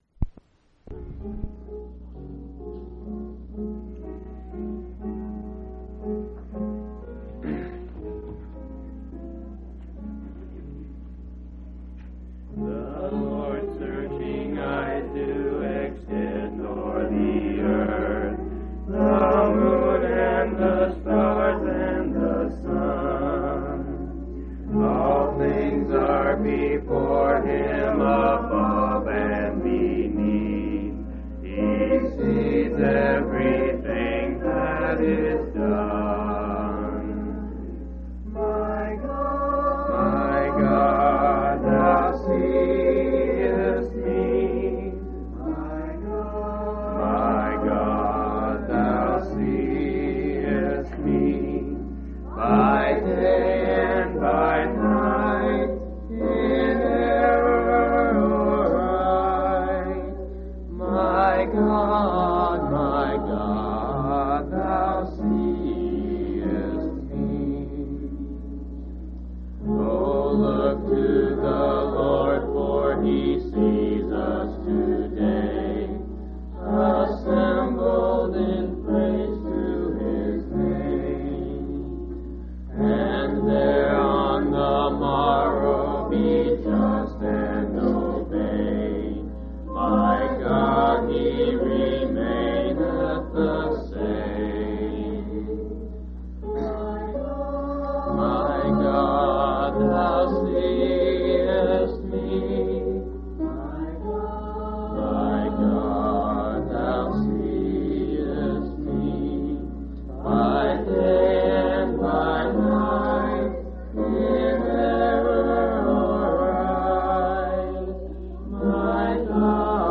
9/22/1985 Location: Phoenix Local Event